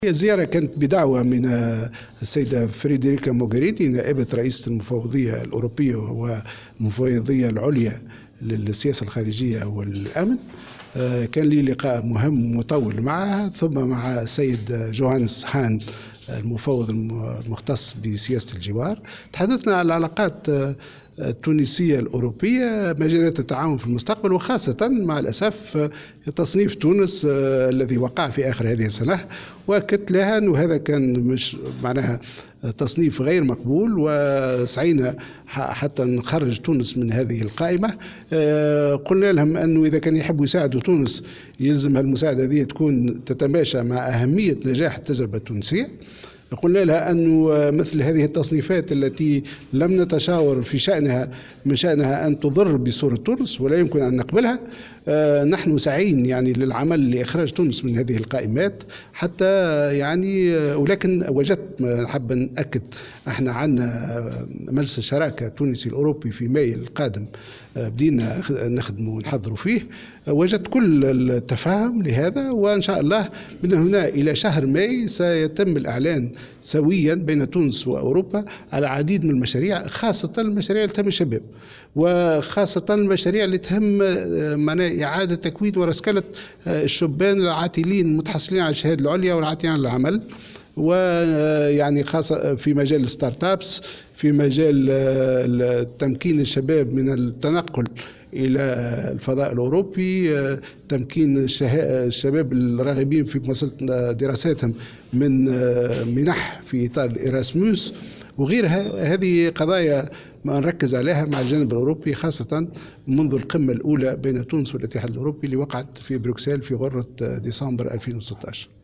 وأضاف في تصريح لمراسلة "الجوهرة أف أم" على هامش اجتماع مع وزير خارجية لوكسمبورغ، أن اللقاء مع "مورغيني" تناول بالخصوص العلاقات التونسية الأوروبية و مجالات التعاون مستقبلا.